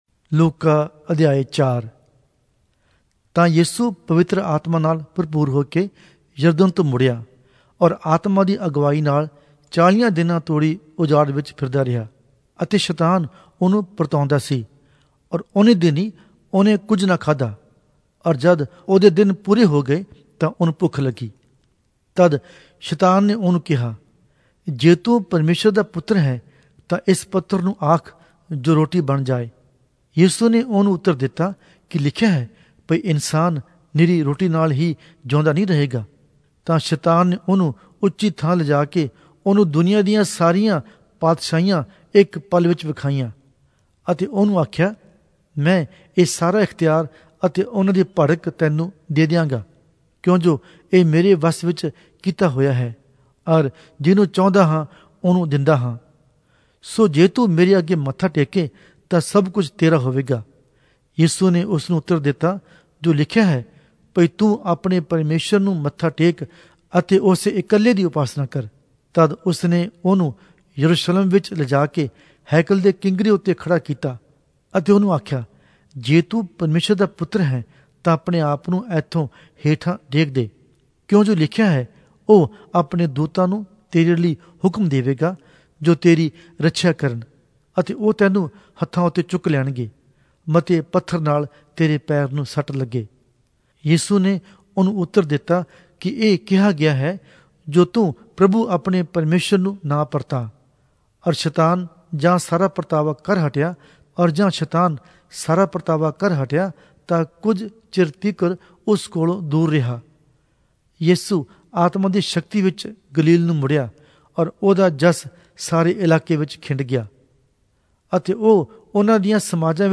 Punjabi Audio Bible - Luke 2 in Gntbrp bible version